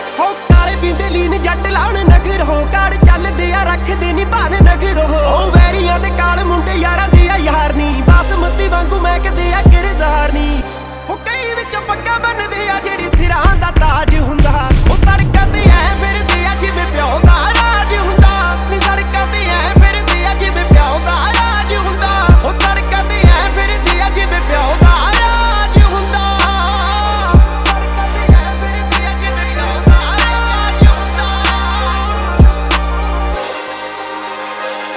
Жанр: Инди / Местная инди-музыка